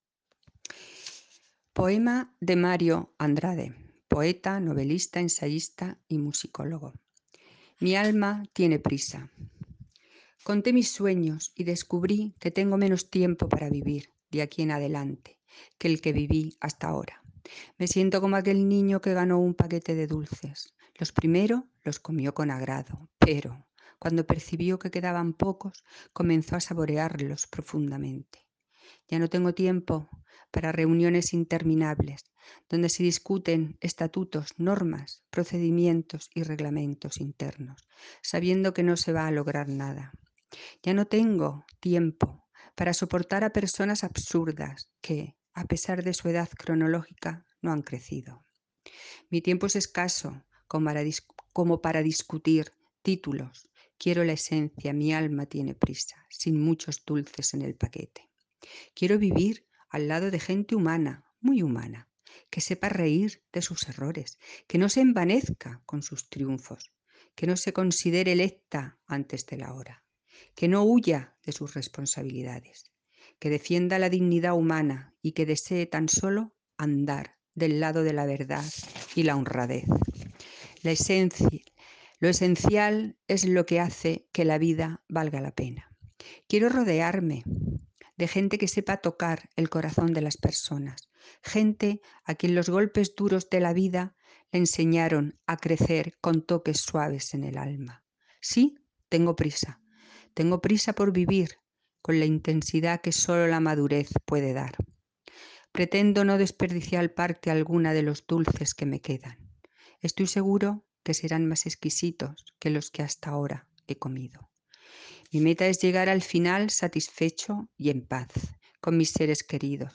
Modalidad poesía
con la lectura de “Mi alma tiene prisa” (Mario de Andrade).